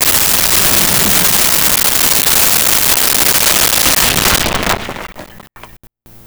Explosion Rocks Debris
Explosion Rocks Debris.wav